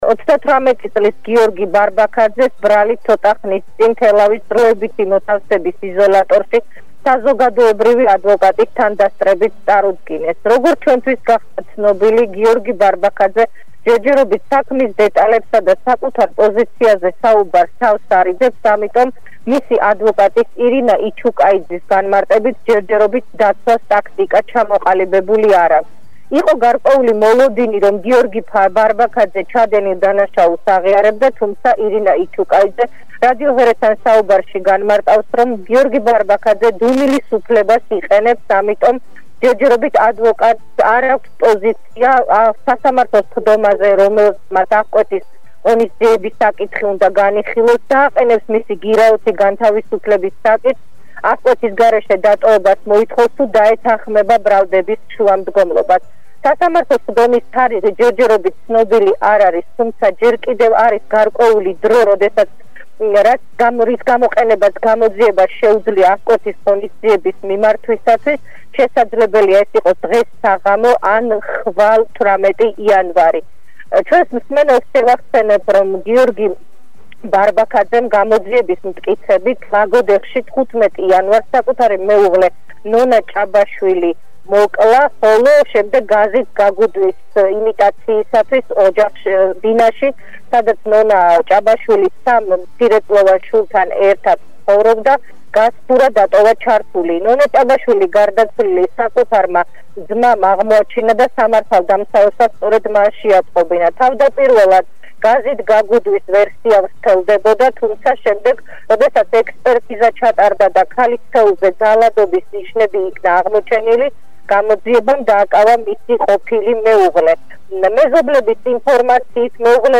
ჩართვას ახალ ამბებში